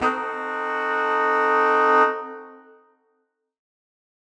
Index of /musicradar/undercover-samples/Horn Swells/C
UC_HornSwell_Cdim.wav